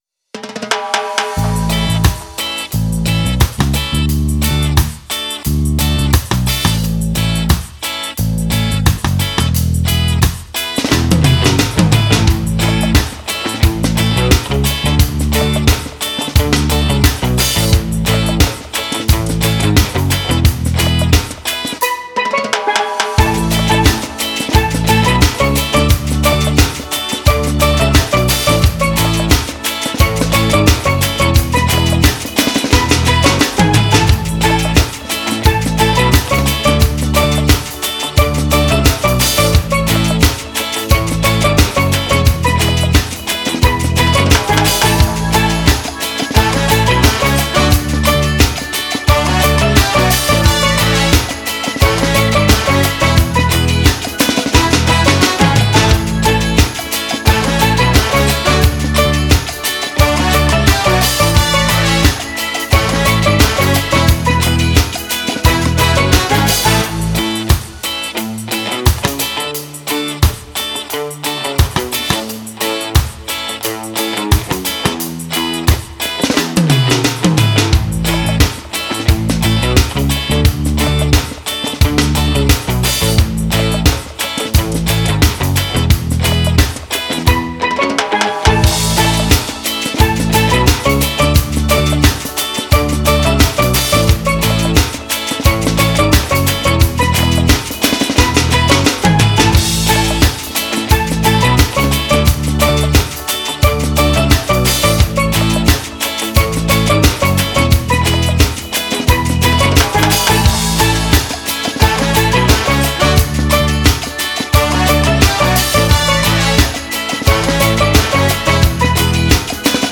Genre: reggaeton, reggae.